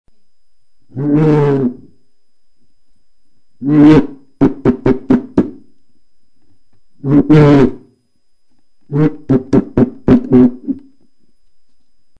URM Sonos de Sardigna : strumenti muicali antichi - Trimpanu